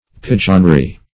Pigeonry \Pi"geon*ry\, n. A place for pigeons; a dovecote.
pigeonry.mp3